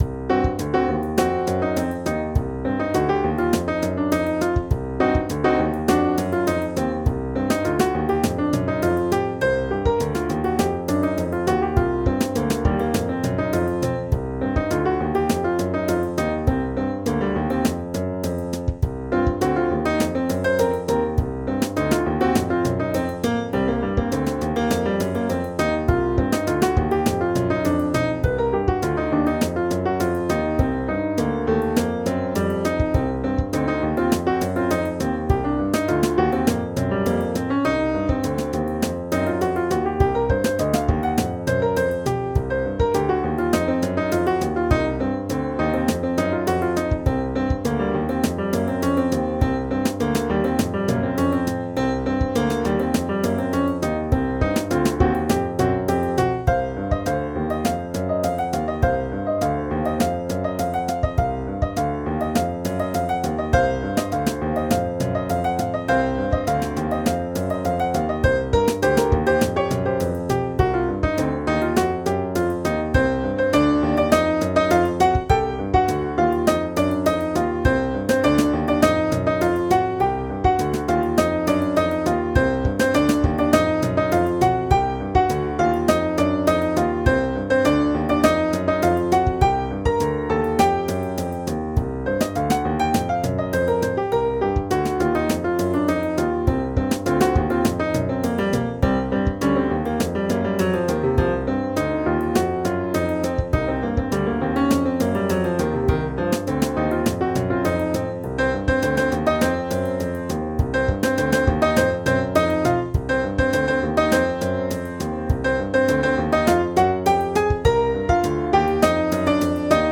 Pieza de jazz
piano
jazz